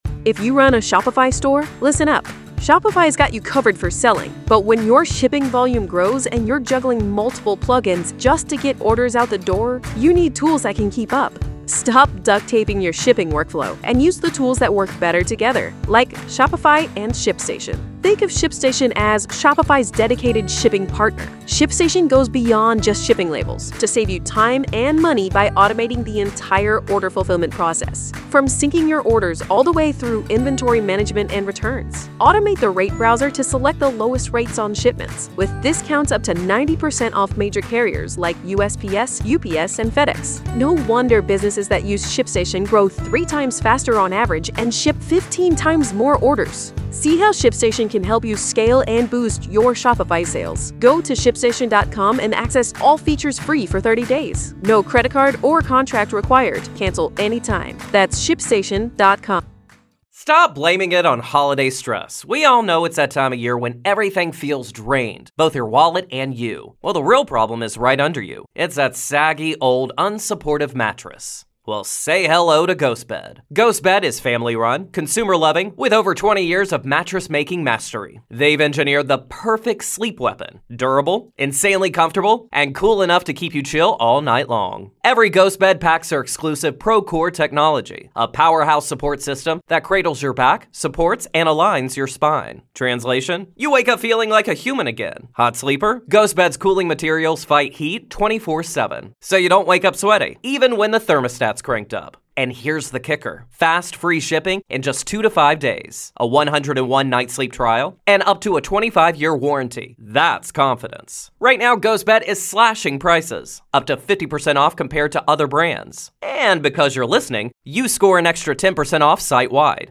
Jury Instructions
LIVE COURTROOM COVERAGE — NO COMMENTARY